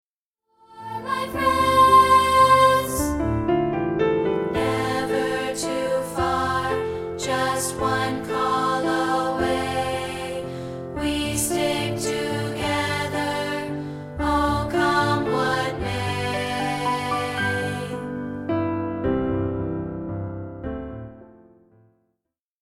rehearsal tracks